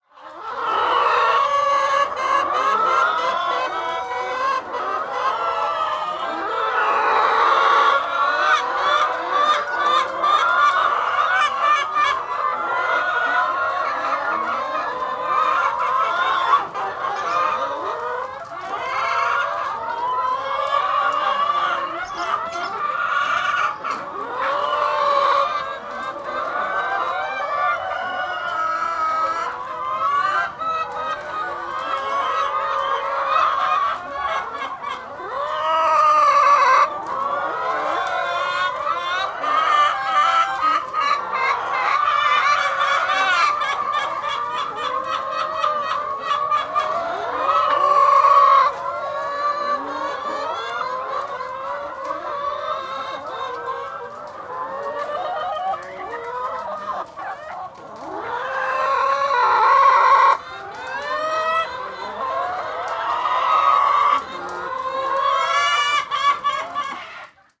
animal
Chicken Clucking 2